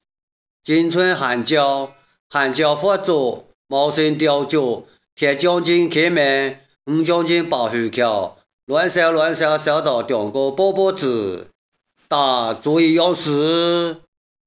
38进村喊叫